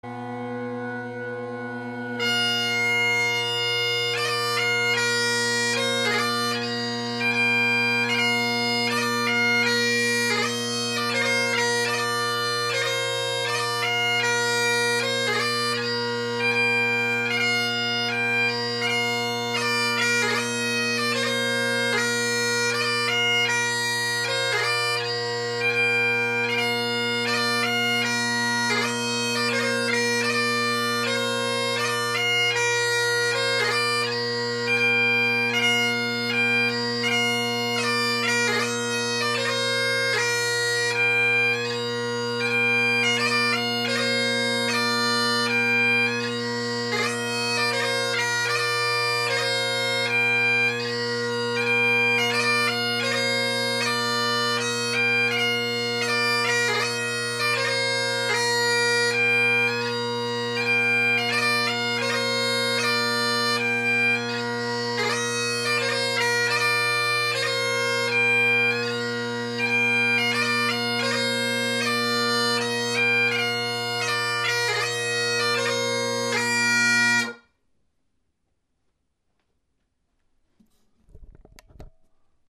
Drone Sounds of the GHB, Great Highland Bagpipe Solo
I play the same tune in each recording; a new composition of mine.
Plastic tongued Canning bass drone reed